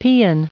added pronounciation and merriam webster audio
1000_paean.ogg